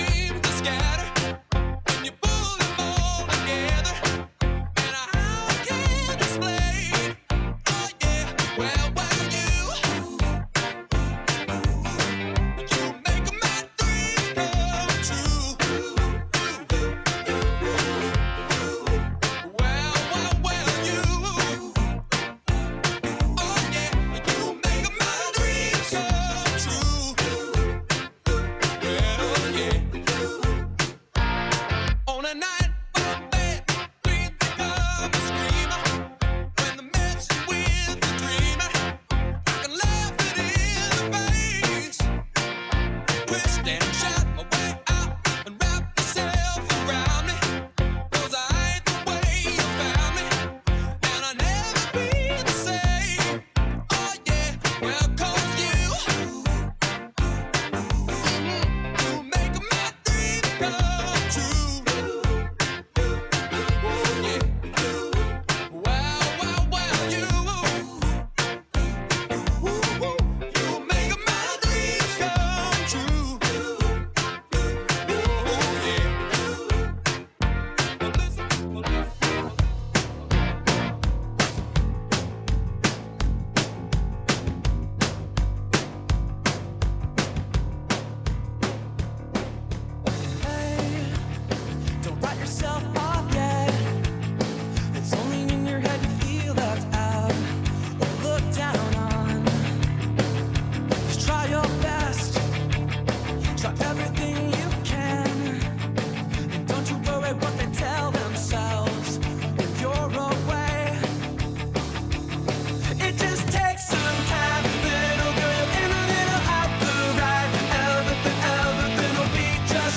A live wedding mix